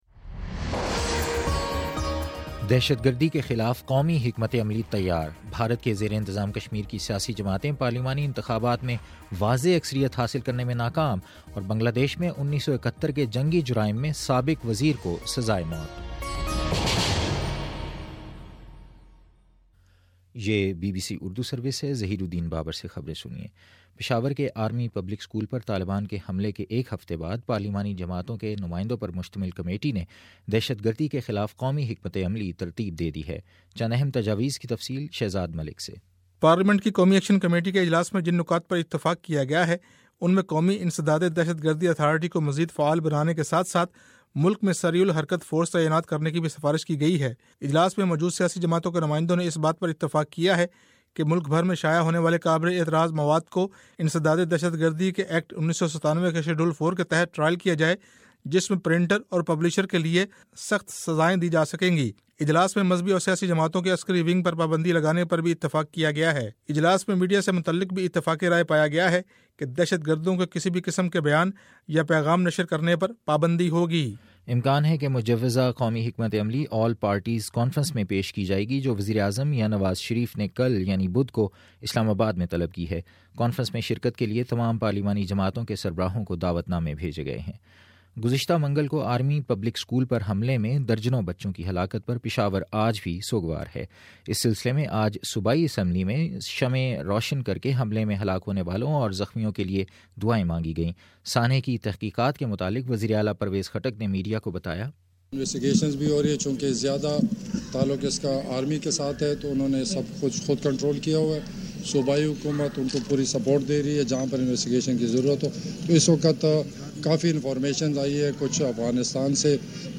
دسمبر23: شام چھ بجے کا نیوز بُلیٹن